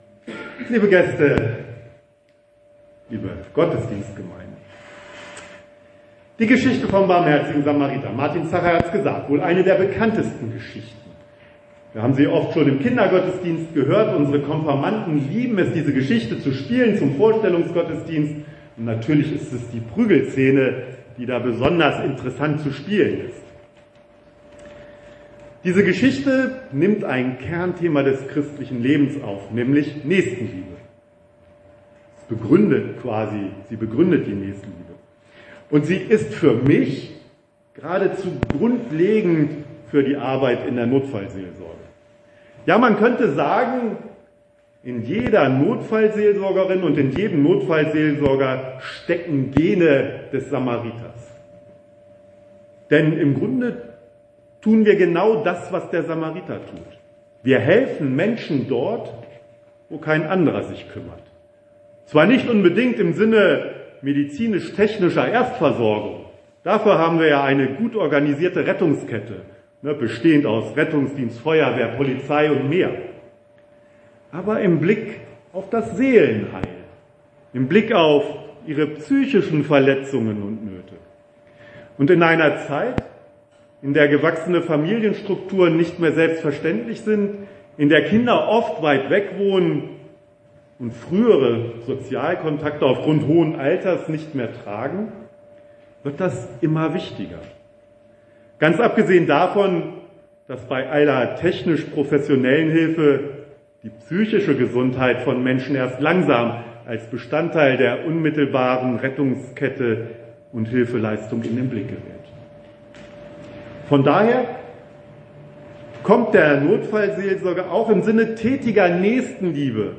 In der Fahrzeughalle der Feuerwehr Schöppenstedt wurden am 6. Oktober in einem feierlichen Gottesdienst kürzlich ausgebildete Ehrenamtliche für ihr Amt in der Notfallseelsorge im Landkreis Wolfenbüttel eingesegnet.